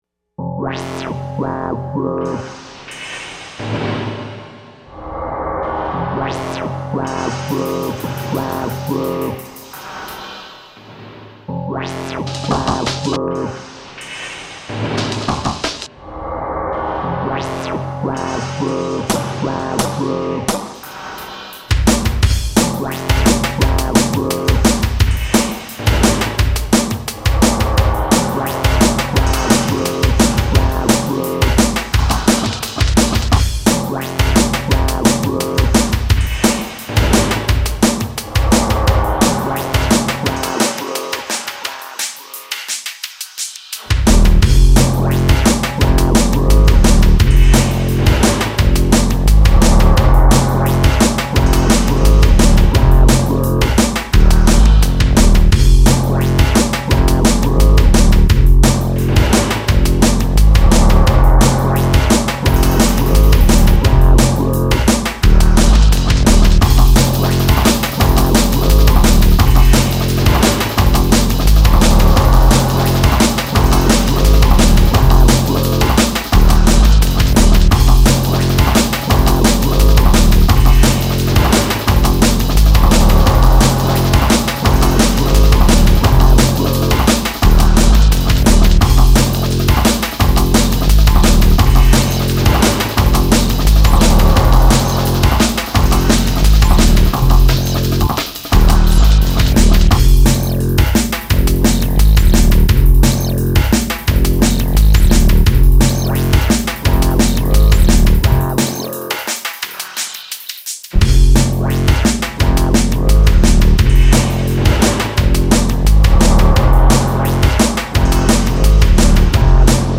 Oooh drum&bass.